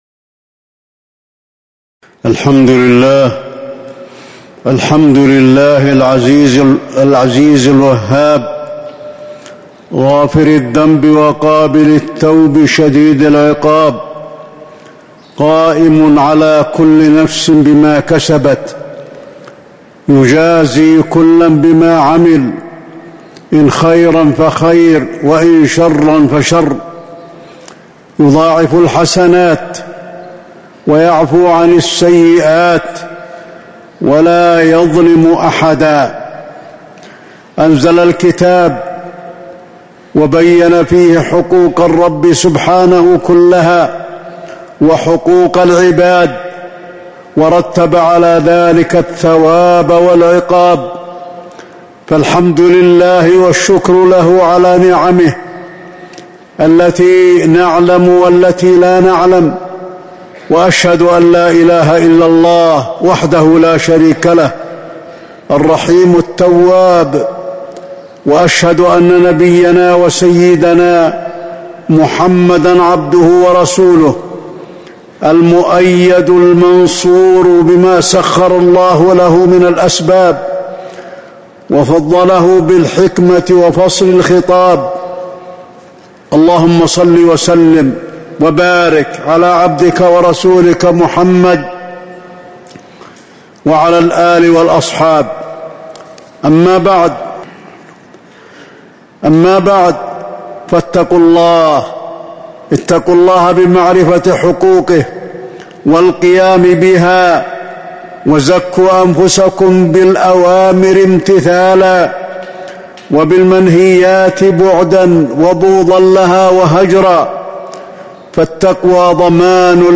تاريخ النشر ١٧ محرم ١٤٤٥ هـ المكان: المسجد النبوي الشيخ: فضيلة الشيخ د. علي بن عبدالرحمن الحذيفي فضيلة الشيخ د. علي بن عبدالرحمن الحذيفي وما خلقت الجن والإنس إلا ليعبدون